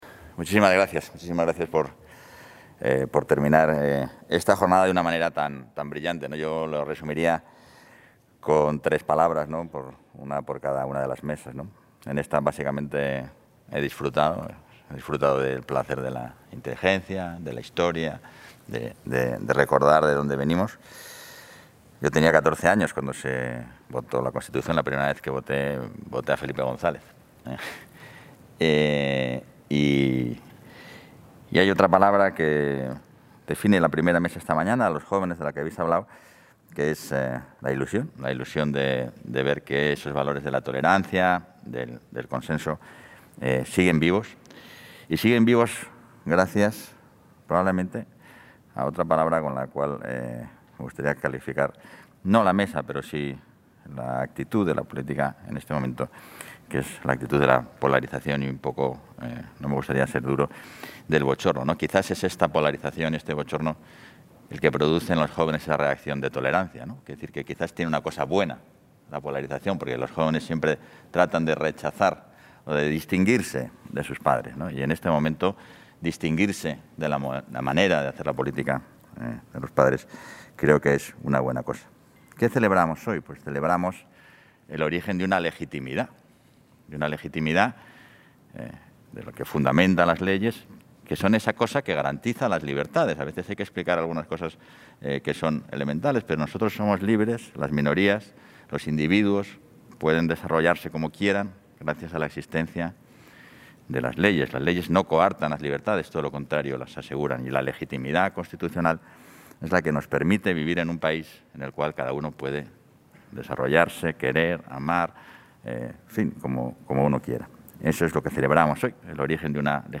Material audiovisual de la clausura a cargo del vicepresidente Igea de la jornada institucional 'Día de la Constitución 2020. El día de todos'
Intervención de clausura del vicepresidente de la Junta.